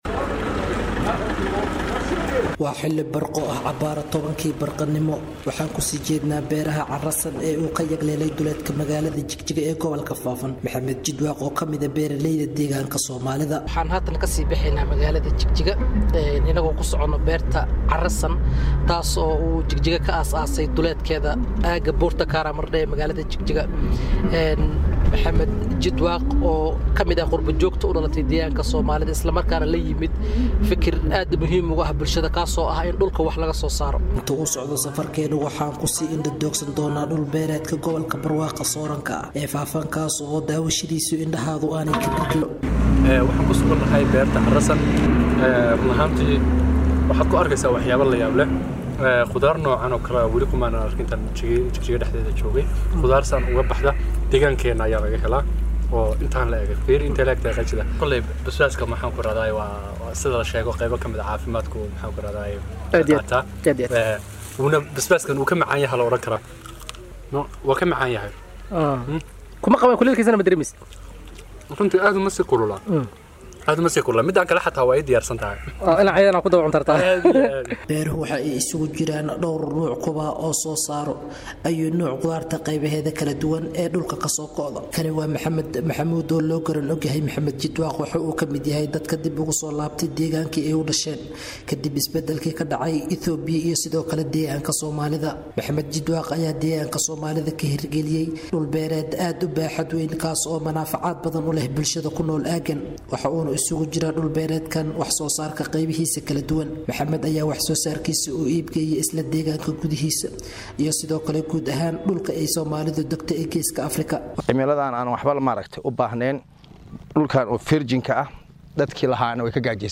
Waxaa soo badanaya dadka ka shaqeeya wax soo saarka beeraha ee dowlad deegaanka somaalida ee Itoobiya Warbixin arrimahasi ku saabsan waxaa Jigjiga inooga soo diray